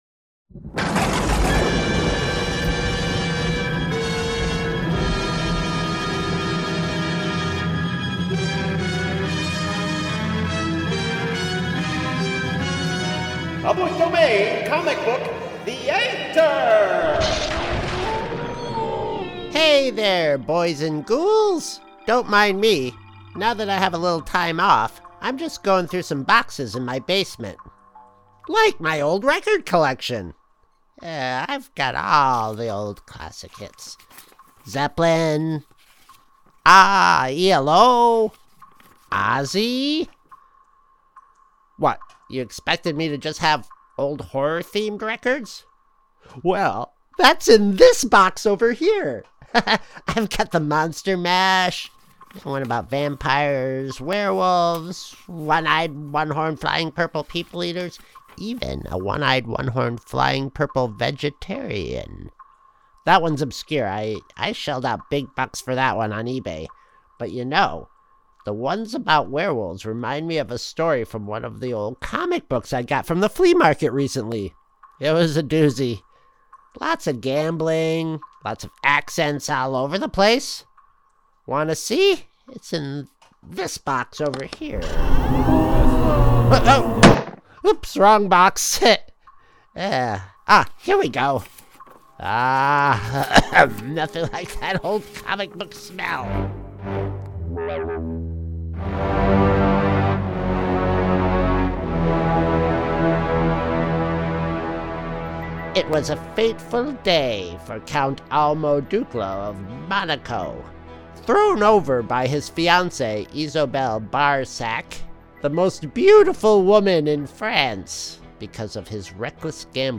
The Ocadecagonagon Theater Group